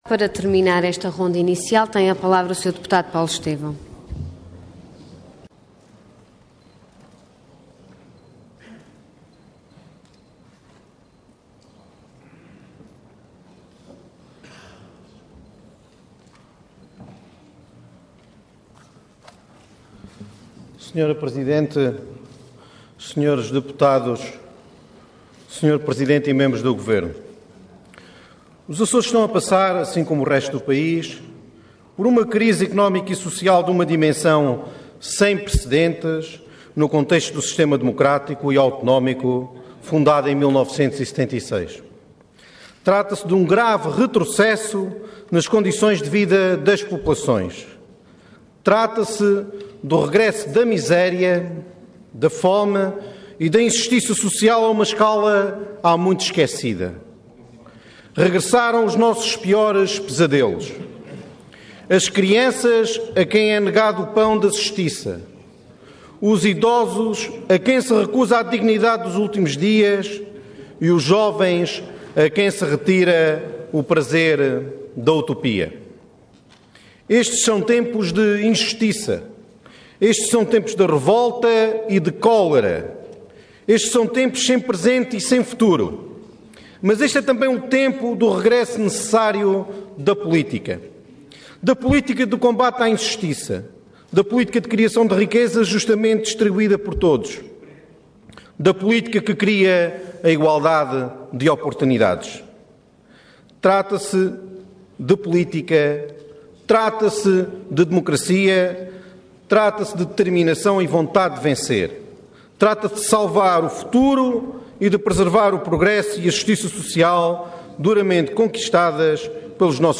Intervenção Intervenção de Tribuna Orador Paulo Estêvão Cargo Deputado Entidade PPM